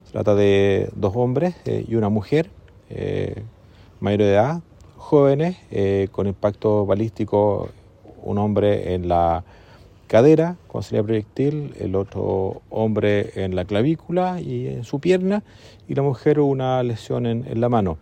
El mismo fiscal Eduardo Jeria detalló que mientras la mujer resultó con una herida en una mano, uno de los hombres quedó lesionado en su cadera y el otro en la clavícula.